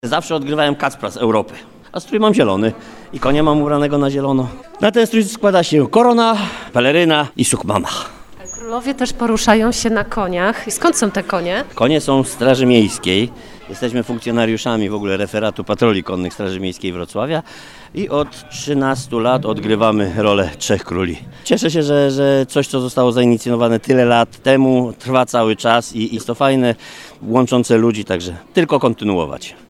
Orszak Trzech Króli we Wrocławiu